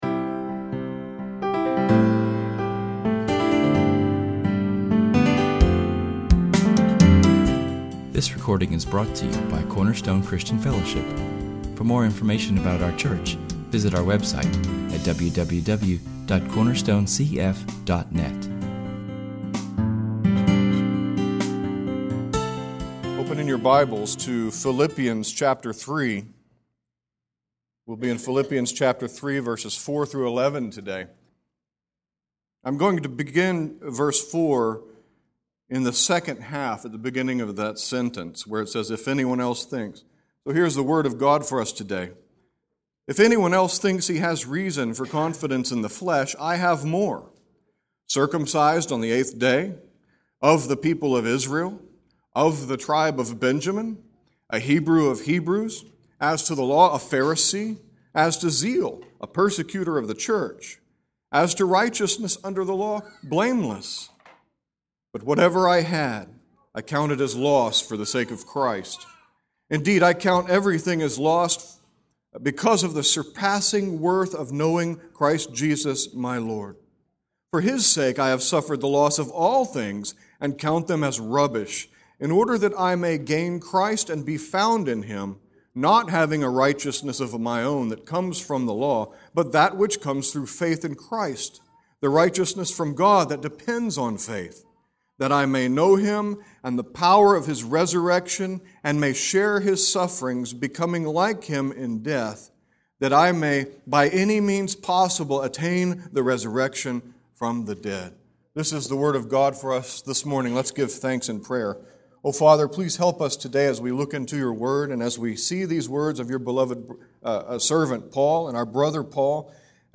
Our sermon is entitled Resurrection Life, and we look today at the power of the resurrection.